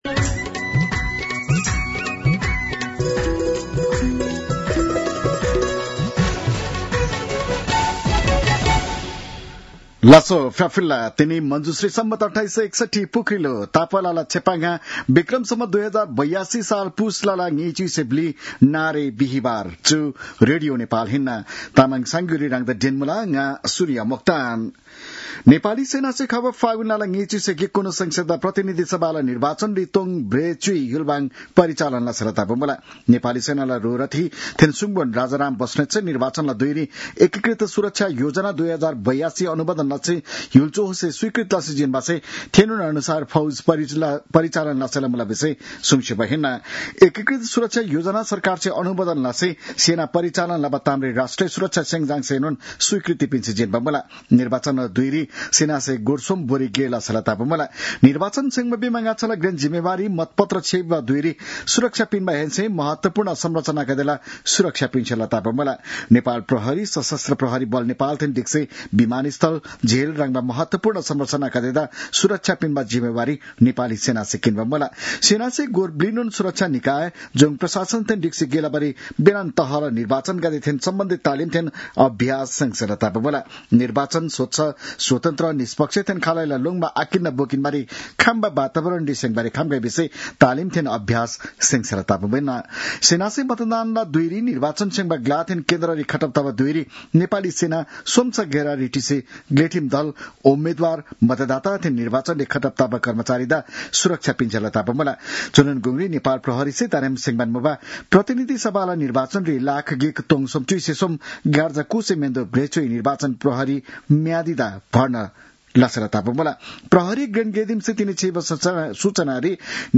तामाङ भाषाको समाचार : २४ पुष , २०८२